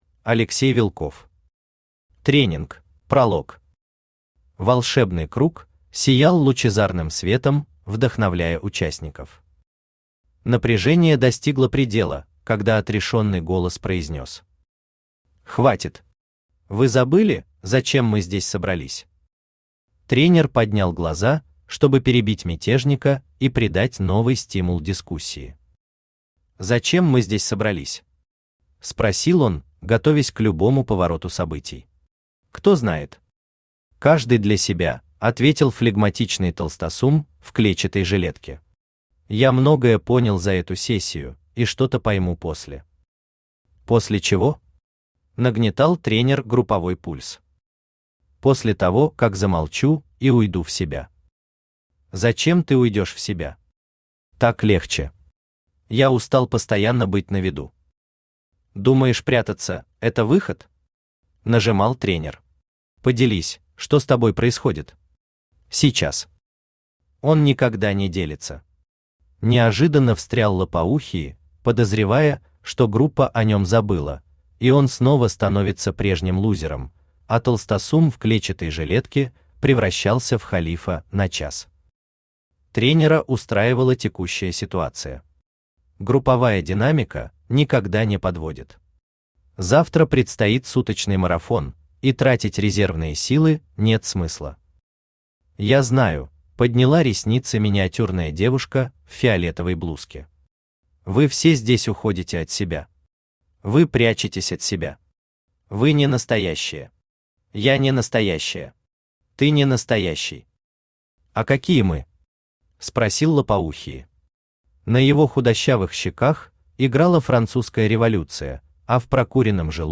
Аудиокнига Тренинг | Библиотека аудиокниг
Aудиокнига Тренинг Автор Алексей Сергеевич Вилков Читает аудиокнигу Авточтец ЛитРес.